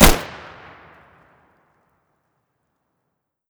Index of /server/sound/xoma_x4_weapons/misc_combine/m249
shoot.wav